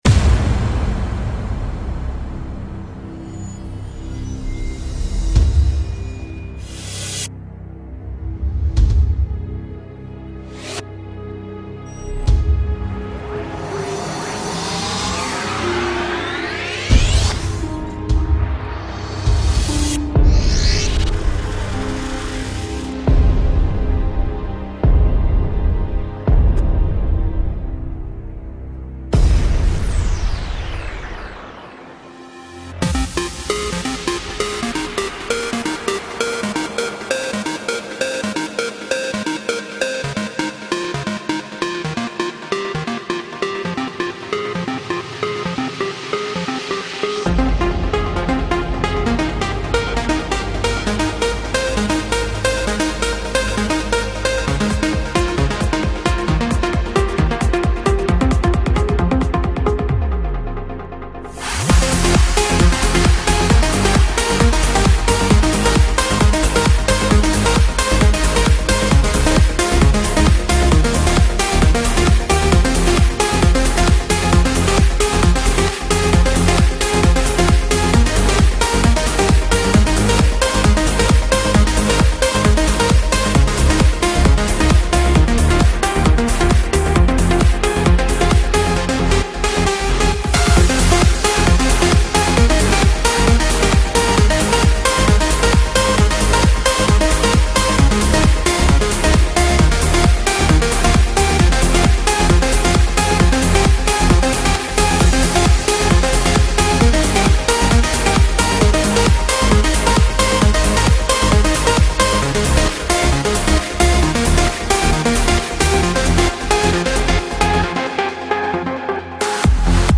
[زبان موسیقی : لایت ( بدون کلام ) ]